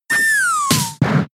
Game: Pokemon SFX Gen 6 - Attack Moves - XY, ORAS (2020)